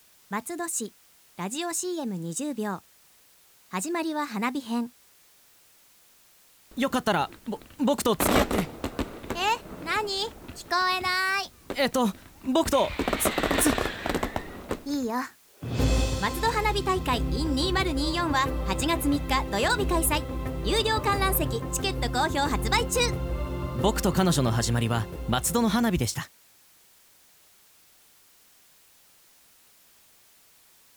ラジオCM放送概要
• 放送媒体／ FMラジオ局 BAYFM78（ベイエフエム）78.0MHz
• 松戸花火大会を訪れた男女2人のショートストーリー仕立てで「松戸花火大会イン2024」をPRします